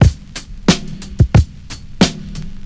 • 90 Bpm Drum Groove C# Key.wav
Free drum loop sample - kick tuned to the C# note. Loudest frequency: 1174Hz
90-bpm-drum-groove-c-sharp-key-J9U.wav